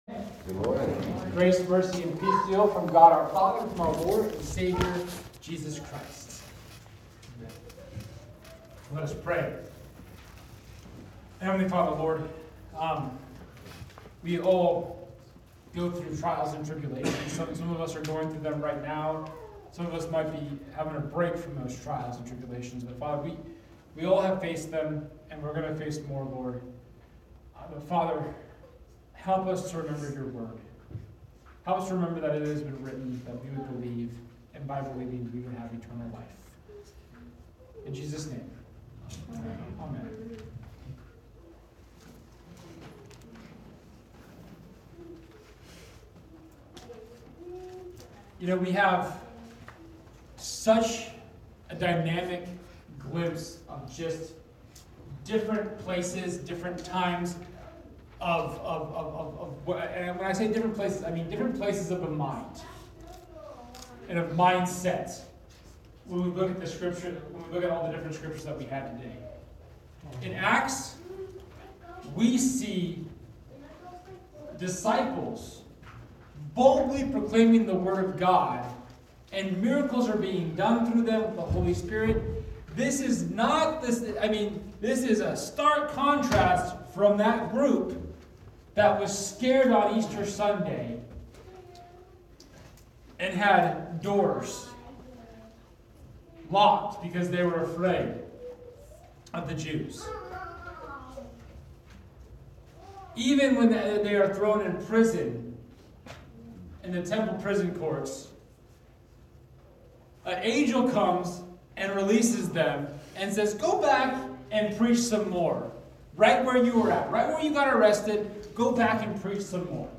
Second Sunday of Easter The audio from today’s sermon is available here.
John 20:19-31 Service Type: Sunday Second Sunday of Easter The audio from today's sermon is available here.